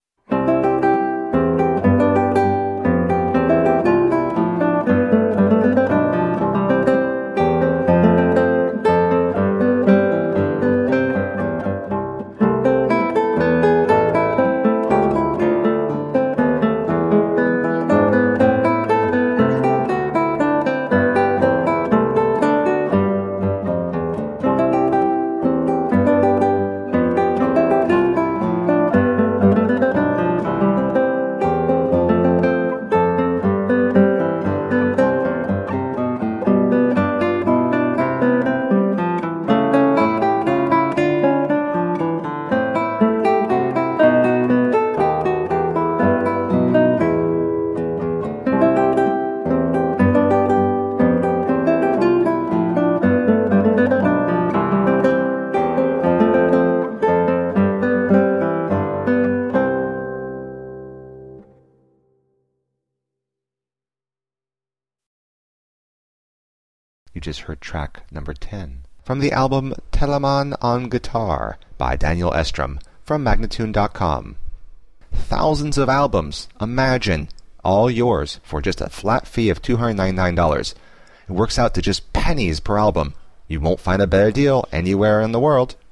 Colorful classical guitar.
Classical, Baroque, Instrumental
Classical Guitar